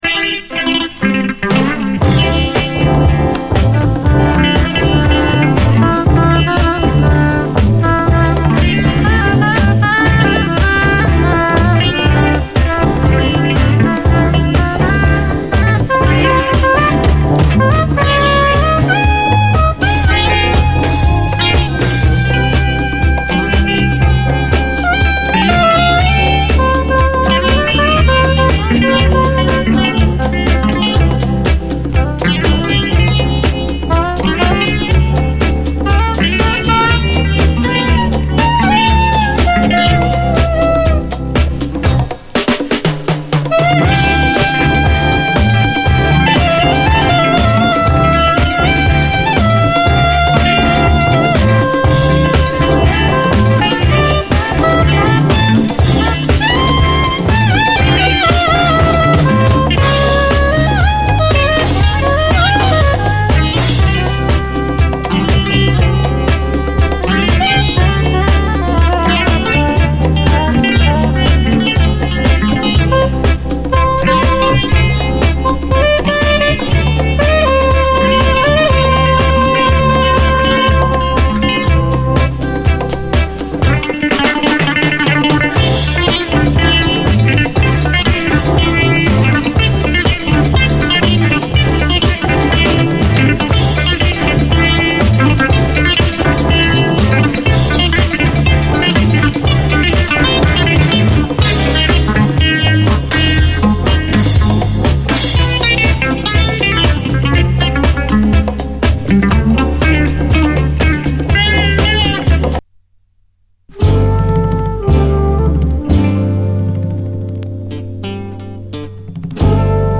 SOUL, 70's～ SOUL, CARIBBEAN
魂を揺さぶるキラー・レアグルーヴ・トラック
ヒューマニティー溢れるメロウ・フォーキー・グルーヴ